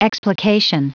Prononciation du mot explication en anglais (fichier audio)
Prononciation du mot : explication